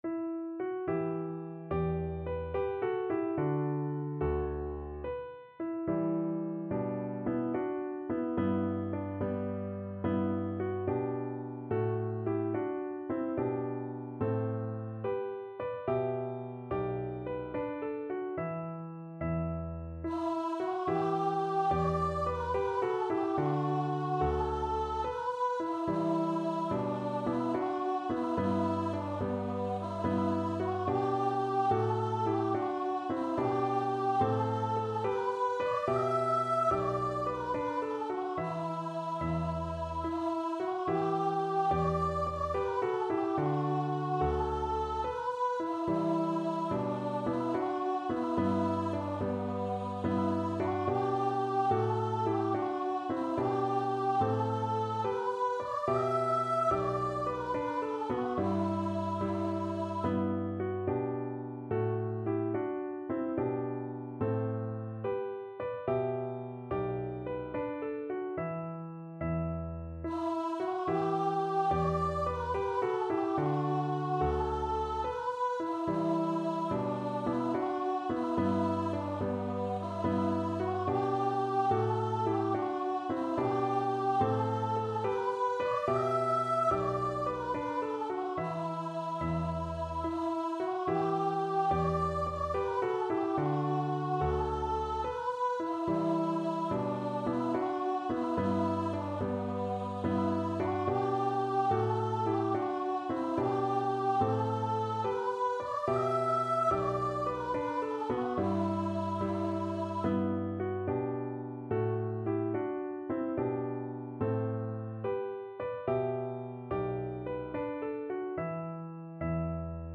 Voice
9/8 (View more 9/8 Music)
Gently .=c.72
E minor (Sounding Pitch) (View more E minor Music for Voice )
Traditional (View more Traditional Voice Music)